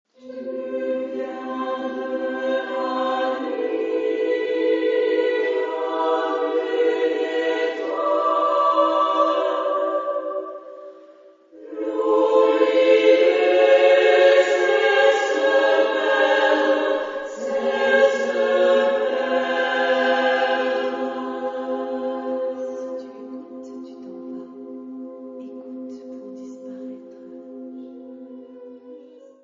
Genre-Style-Forme : Profane ; Poème ; contemporain
Type de choeur : SMA  (3 voix égales OU égales de femmes )
Tonalité : do majeur